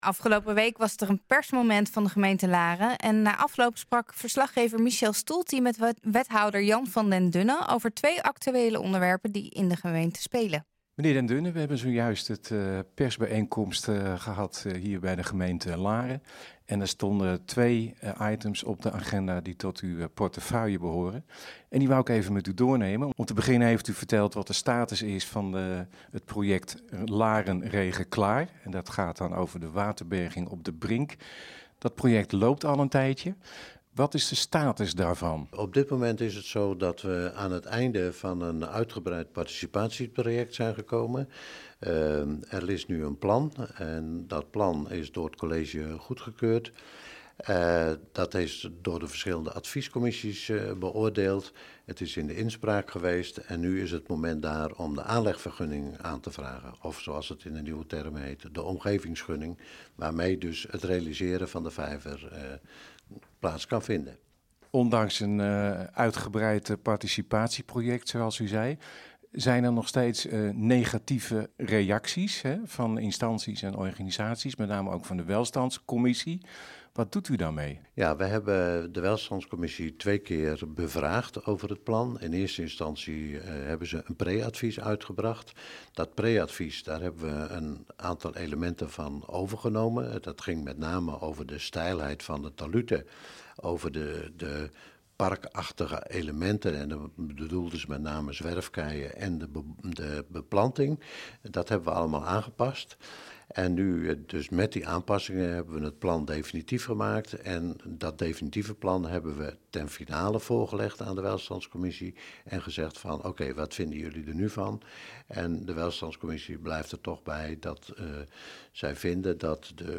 Afgelopen week was er een persmoment van de gemeente Laren.